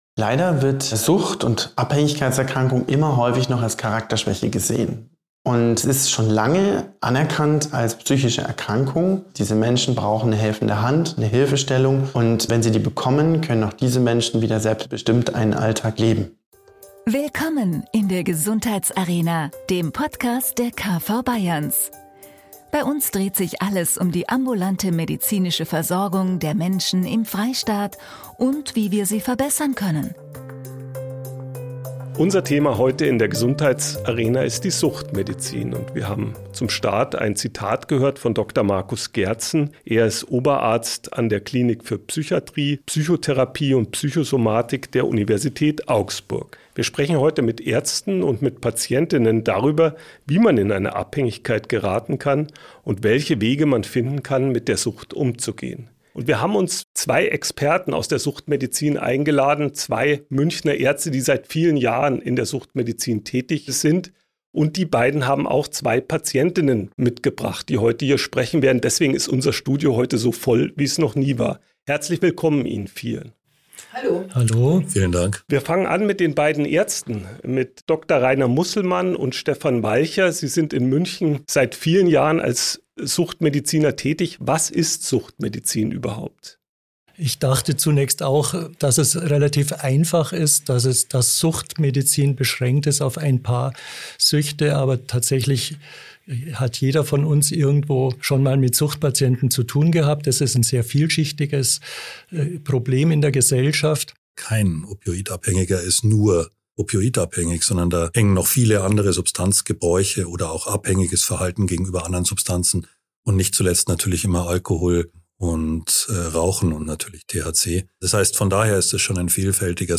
Mit im Studio sind außerdem zwei Patientinnen, die ihre Lebensgeschichten teilen und darüber berichten, wie ihnen die Substitution dabei hilft, ihren Lebensalltag zu bestreiten.